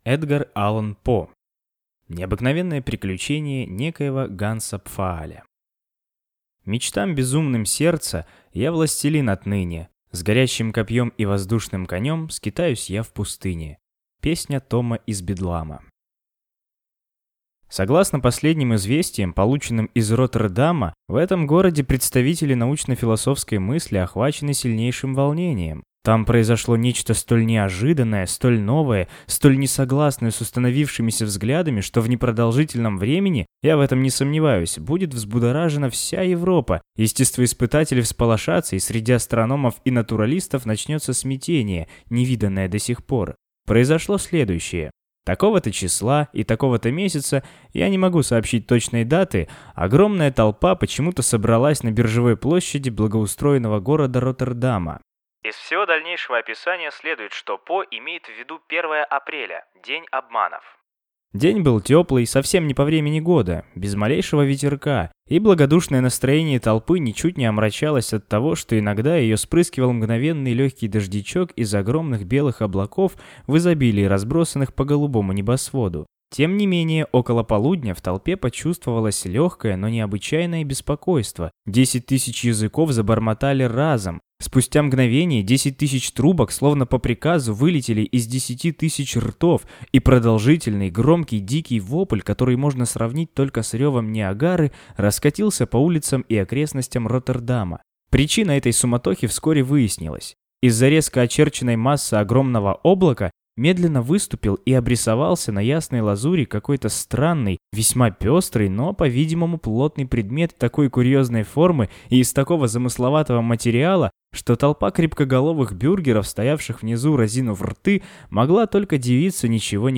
Аудиокнига Необыкновенное приключение некоего Ганса Пфааля | Библиотека аудиокниг